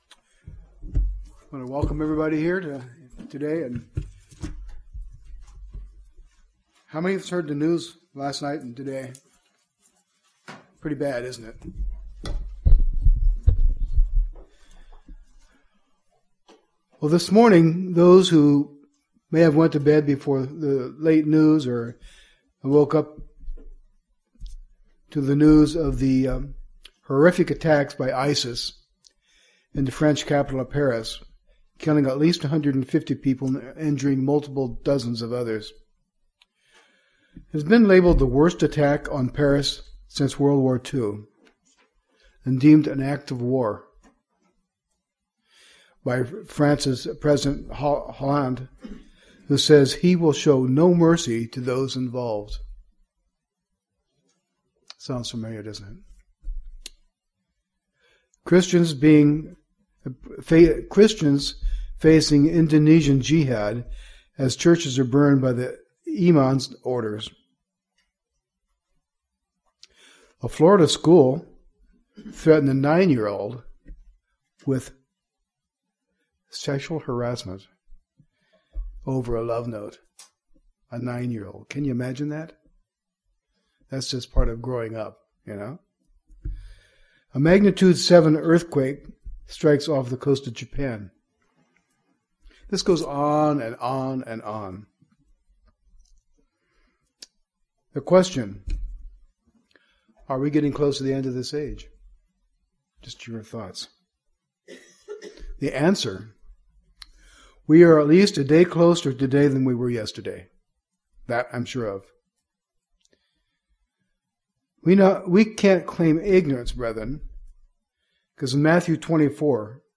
Given in Northwest Arkansas
UCG Sermon Studying the bible?